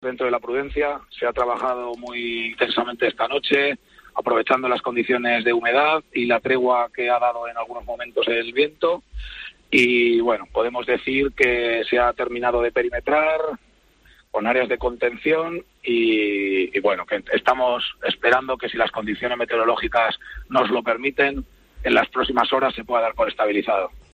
Declaraciones en Herrera en COPE del viceconsjero de Medio Ambiente, Fernando Marchán